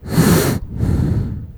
gas_mask_hard_breath5.wav